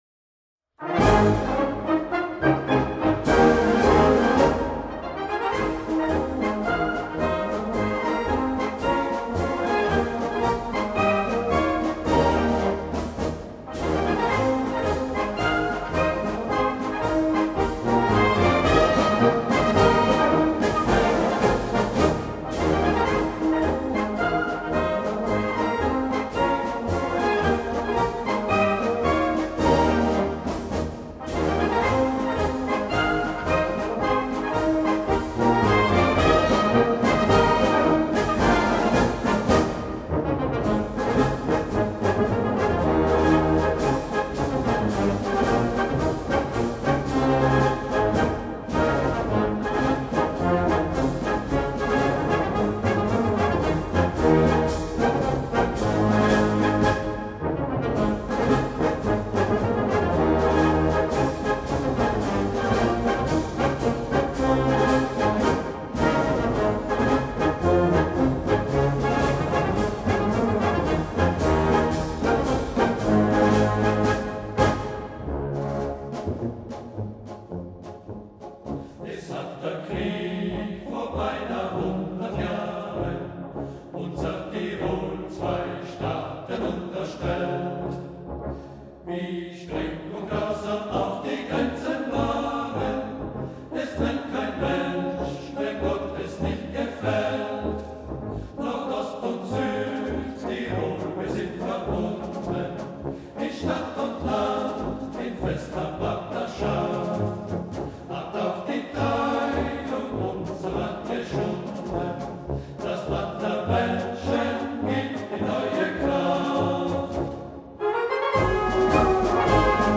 Gattung: Marsch mit Triogesang (inkl. CD)
Besetzung: Blasorchester